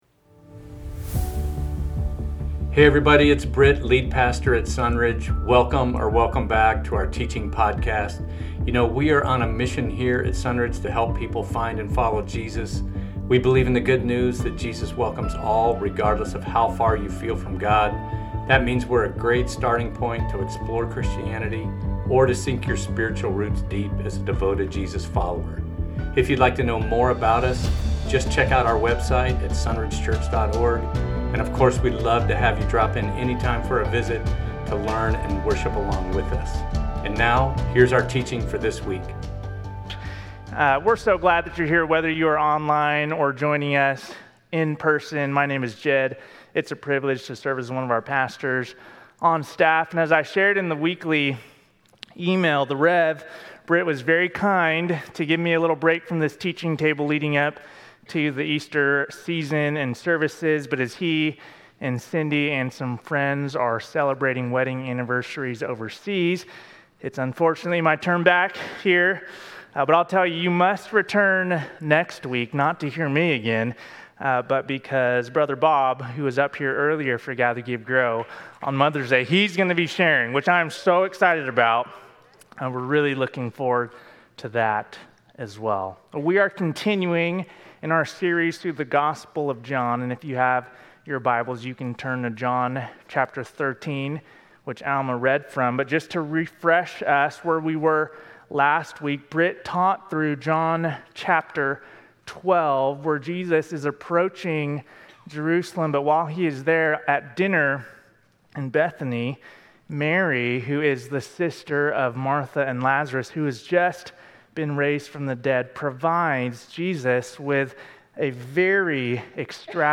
Loved to the End - Sermons at Sunridge Church in Temecula.
Sermon Audio